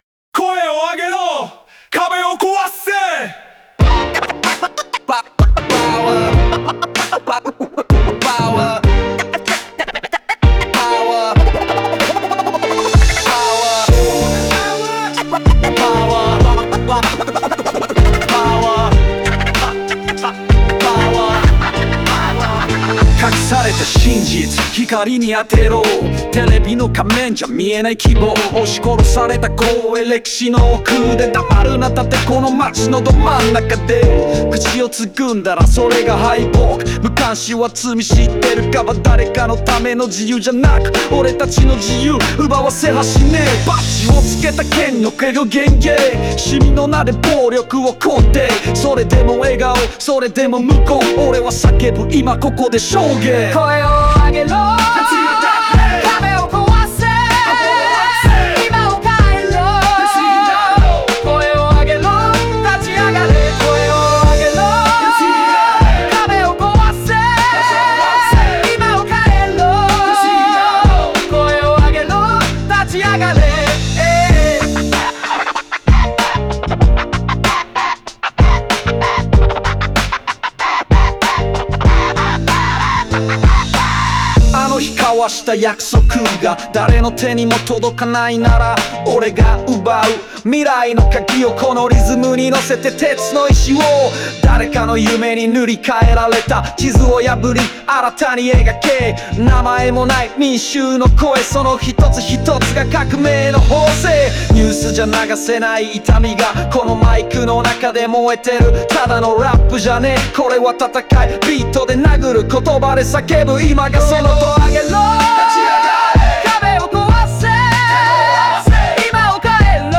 ラップとコーラスによるコール＆レスポンスが連帯感を生み、音楽を単なる娯楽ではなく「武器」として機能させる構成が特徴的だ。